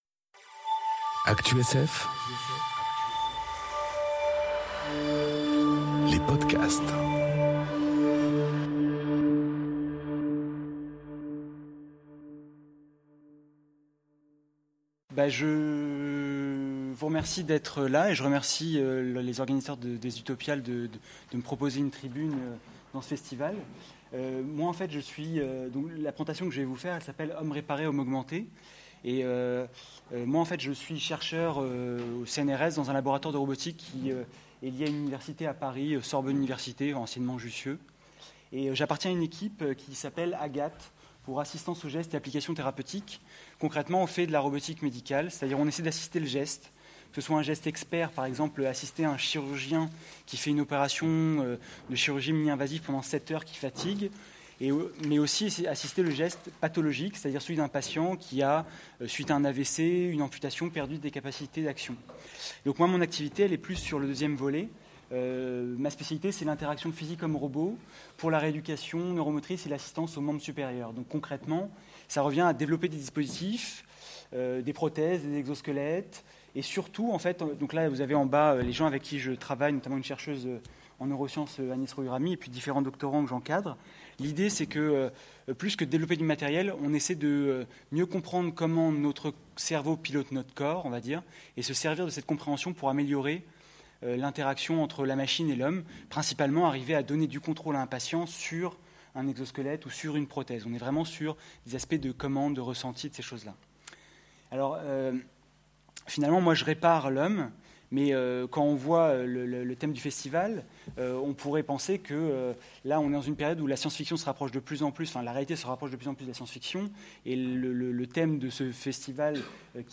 Conférence Homme réparé, homme augmenté ? enregistrée aux Utopiales 2018